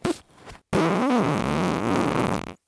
FART SOUND 35
Category 🤣 Funny